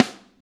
high damp snare f.wav